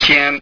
tian1.rm